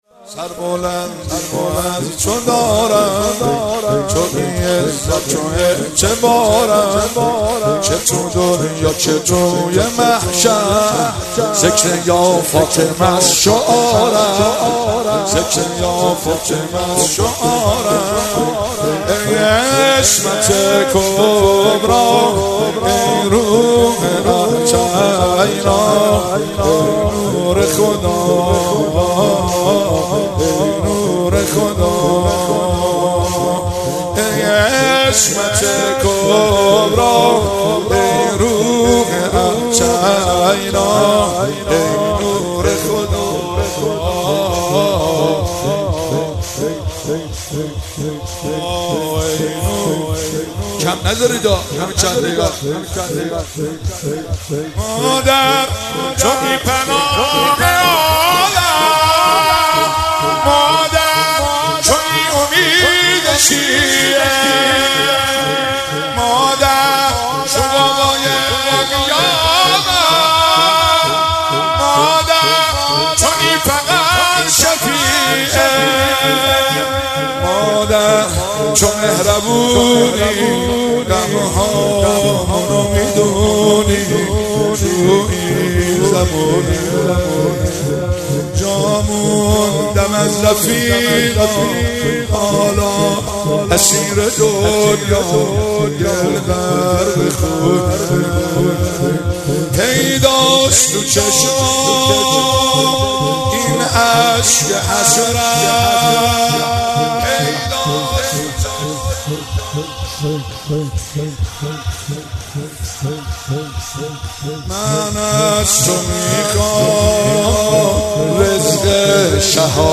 13 دی 96 - هیئت فاطمیون - شور - سربلندی موازتودارم